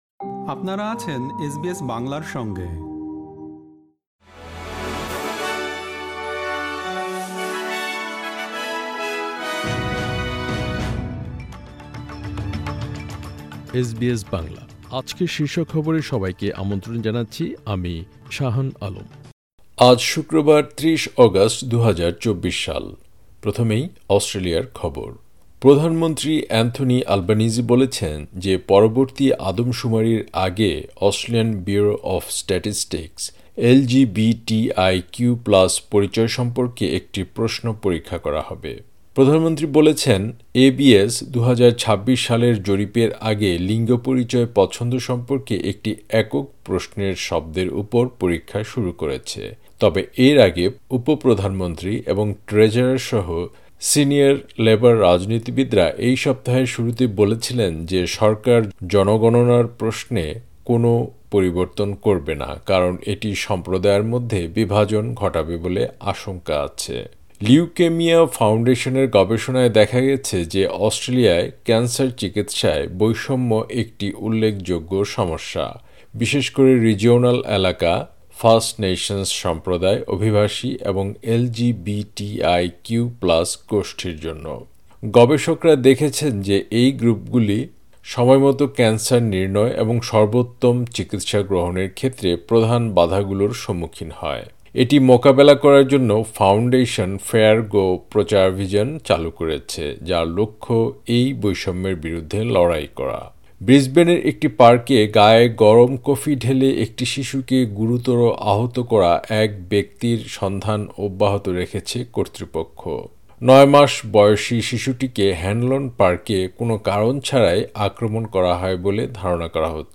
এসবিএস বাংলা শীর্ষ খবর: ৩০ অগাস্ট, ২০২৪